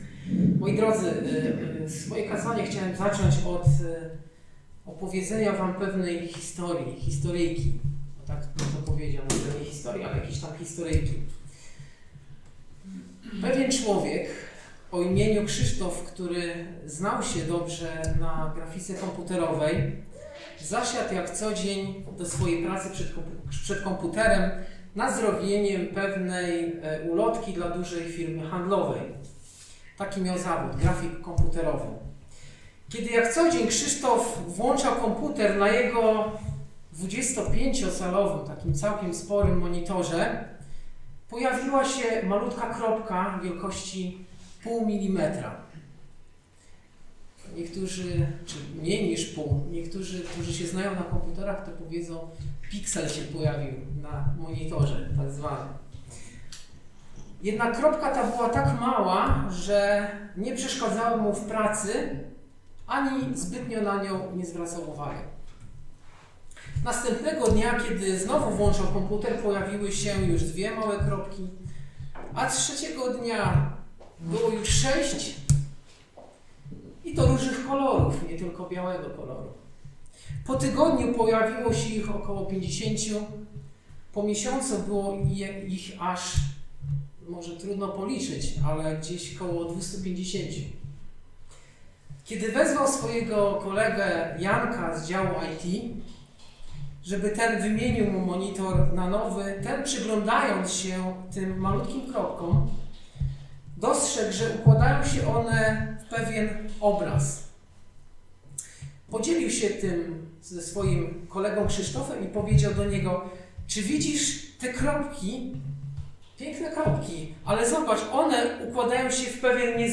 Posłuchaj kazań wygłoszonych w Zborze Słowo Życia w Olsztynie.